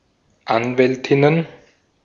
Ääntäminen
Ääntäminen Tuntematon aksentti: IPA: /ˈanvɛltɪnən/ Haettu sana löytyi näillä lähdekielillä: saksa Käännöksiä ei löytynyt valitulle kohdekielelle. Anwältinnen on sanan Anwältin monikko.